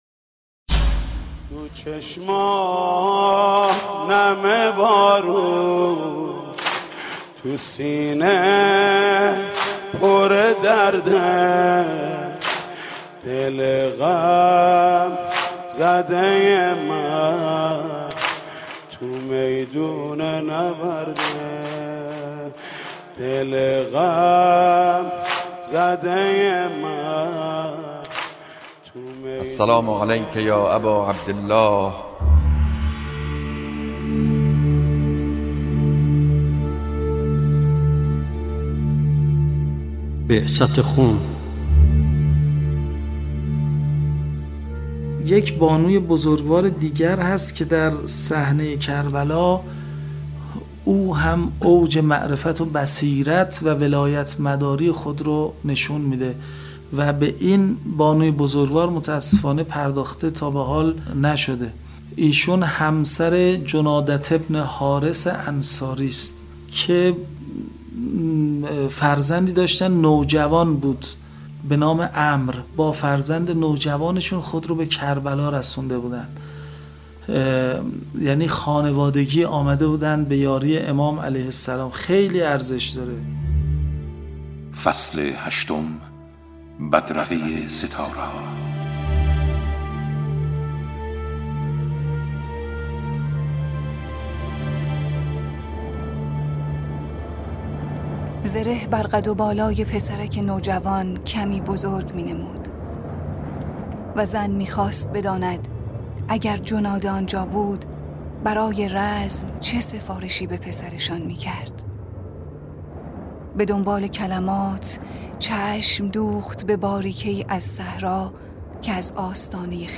ذکر مصیبت، مداحی، شعرخوانی و روایت تاریخی این رویداد